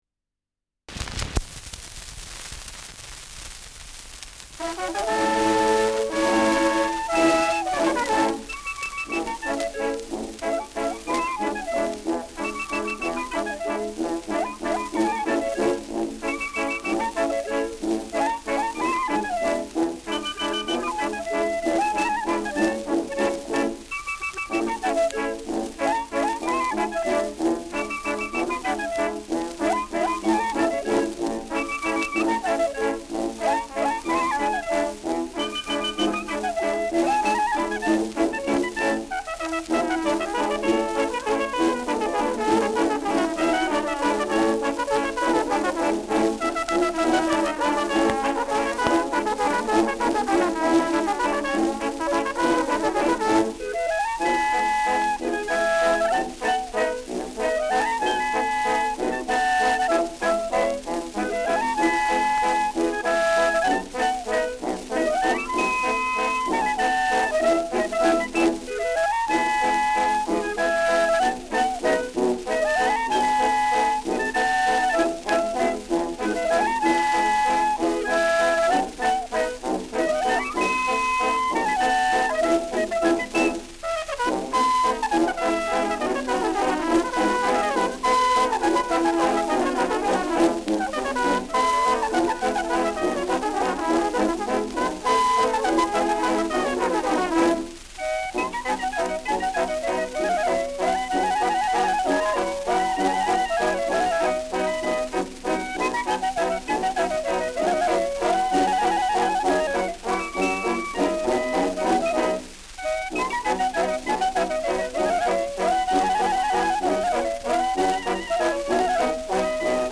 Schellackplatte
Stärkeres Grundrauschen : Gelegentlich leichtes Knacken
[Ansbach] (Aufnahmeort)